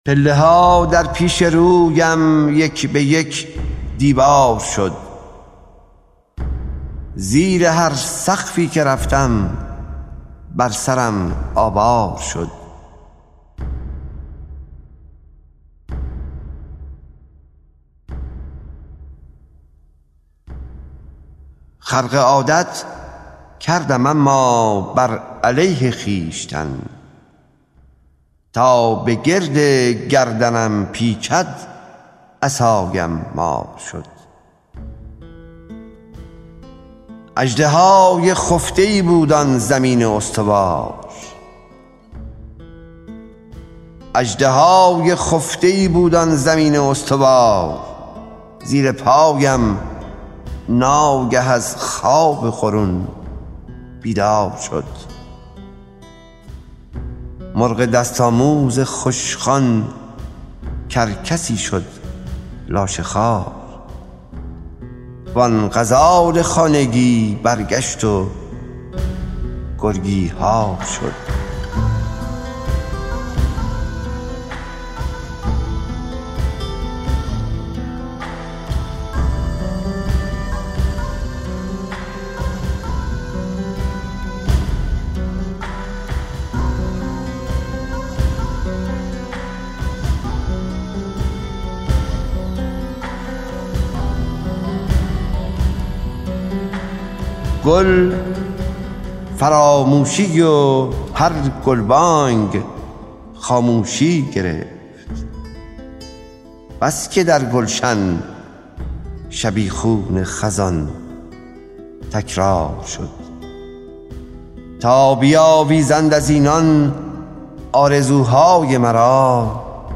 دانلود دکلمه پله ها در پیش رویم با صدای حسین منزوی
گوینده :   [حسین منزوی]